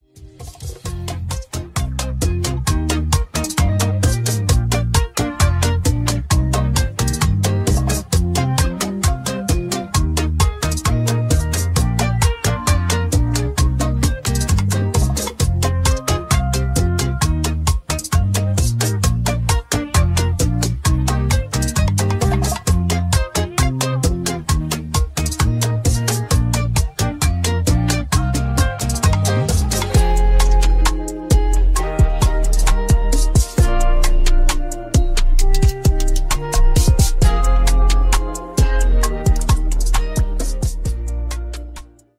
Electrónica